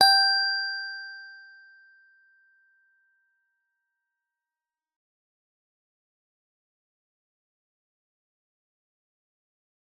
G_Musicbox-G5-f.wav